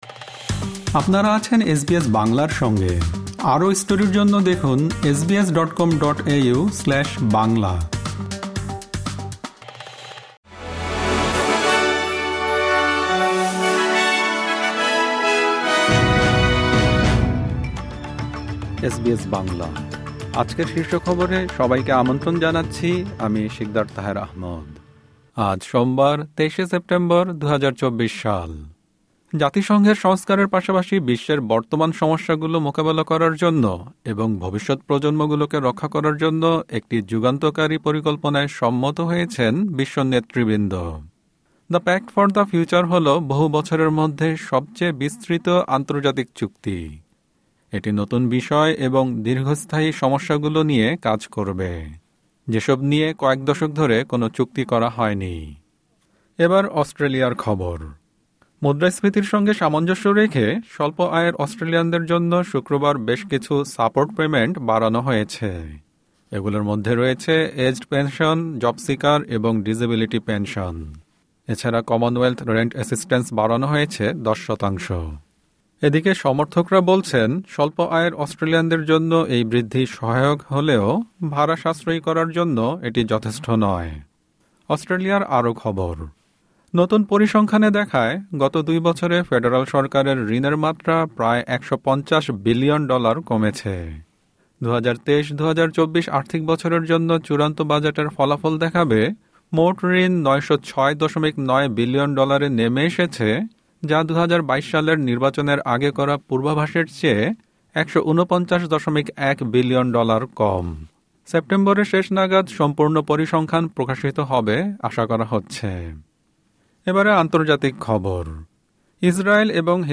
এসবিএস বাংলা শীর্ষ খবর: ২৩ সেপ্টেম্বর, ২০২৪